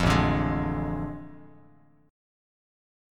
AM7sus4#5 chord